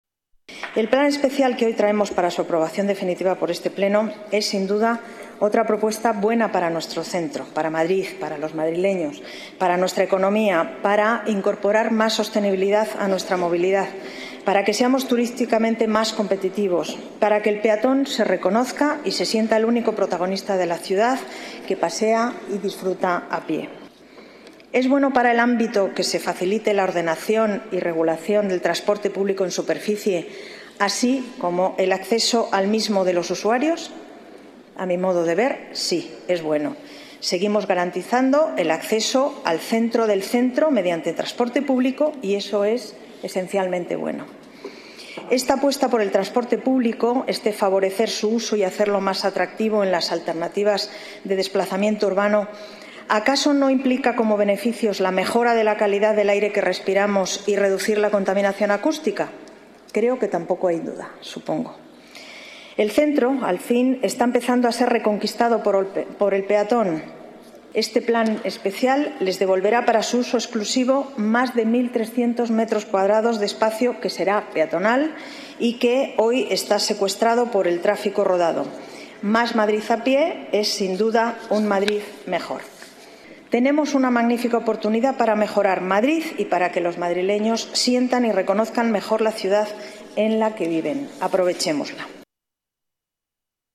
Nueva ventana:Declaraciones delegada Urbanismo, Paz González: proyecto Sevilla-Canalejas